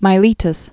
(mī-lētəs)